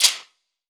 TC2 Perc4.wav